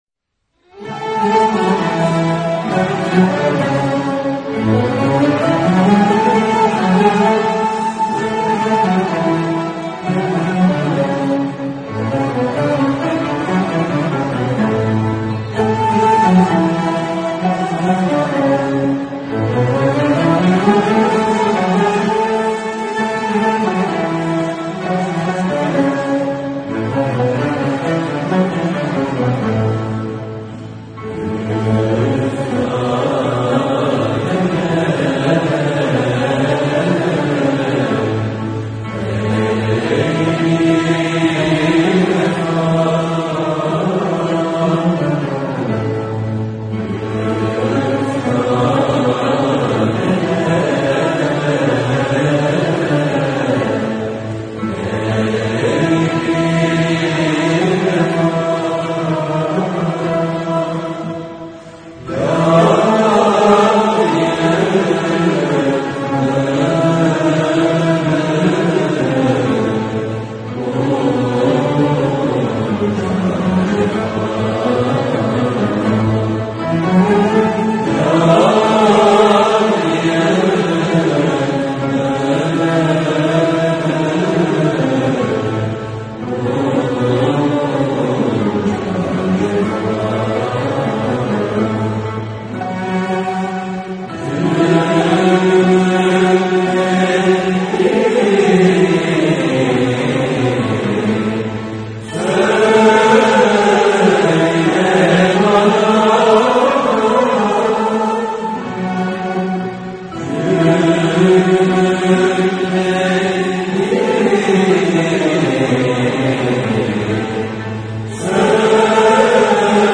Eser: Üftadenem ey bi-vefa Bestekâr: Dede Efendi Güfte Sâhibi: Şemseddin Sivasi Makam: Rast Form: Şarkı Usûl: Düyek Güfte: - Kaynak: Sanat Müziği...